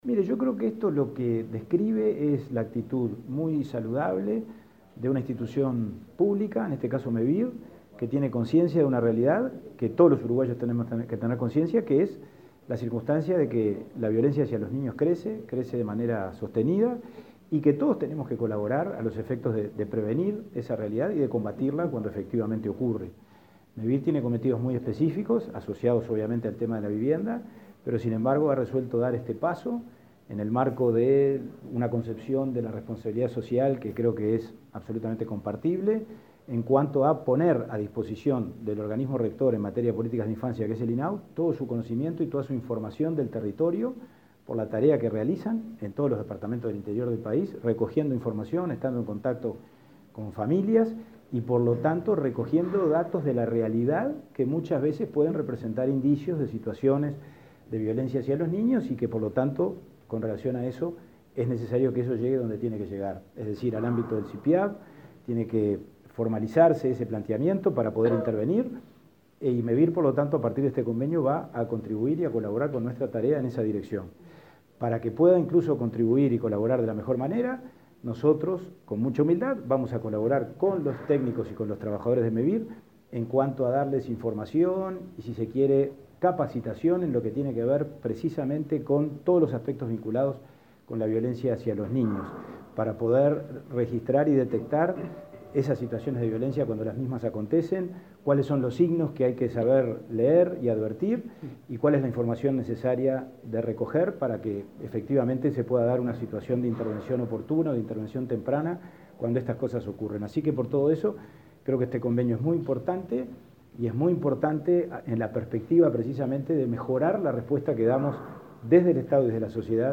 Declaraciones del presidente del INAU, Pablo Abdala
Declaraciones del presidente del INAU, Pablo Abdala 24/10/2022 Compartir Facebook X Copiar enlace WhatsApp LinkedIn El Instituto del Niño y el Adolescente del Uruguay (INAU) y Mevir firmaron un convenio con el objetivo de tomar acciones conjuntas frente a situaciones de violencia detectadas en niños y adolescentes de familias participantes de programas de Mevir. Luego el titular del INAU, Pablo Abdala, dialogó con la prensa.